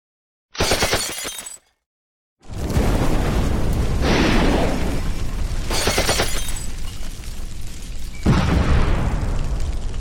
KJFvXHudtUr_Efectos-disparos-fuego-y-explosion-152kbit-Opus-.ogg